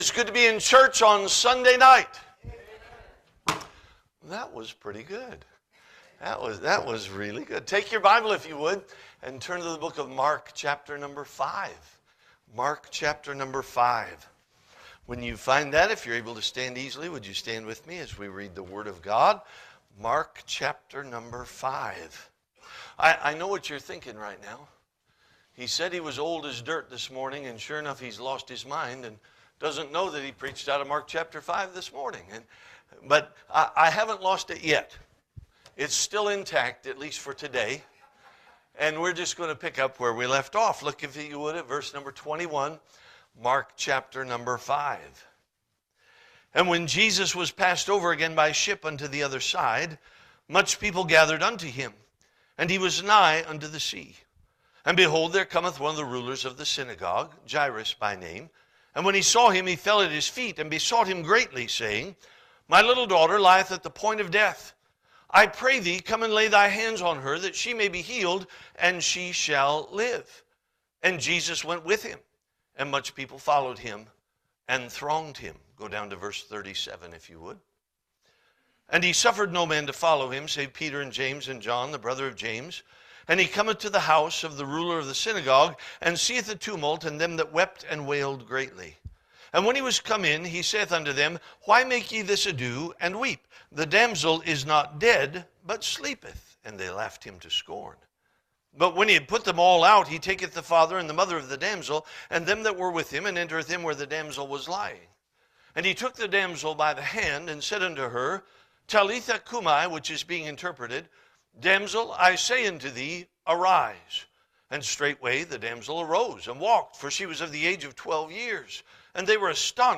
Spring Revival 2026 – Sunday Evening